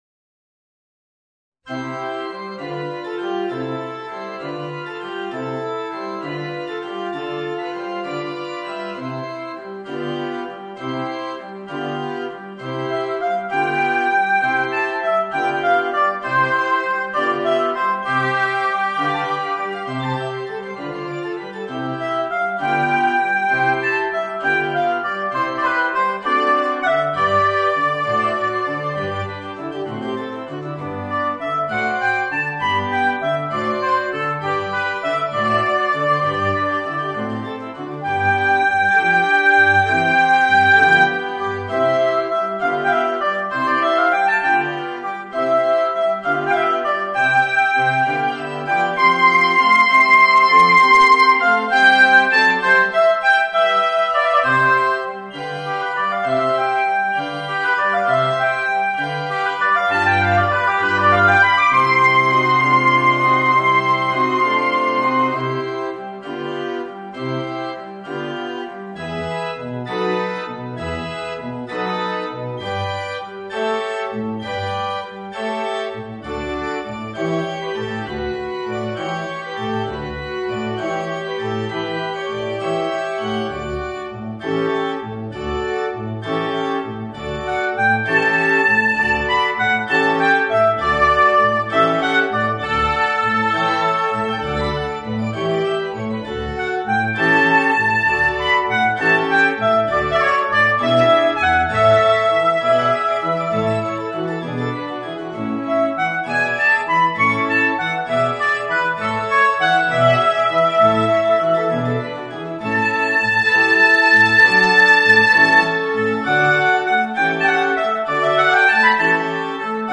Voicing: Oboe and Organ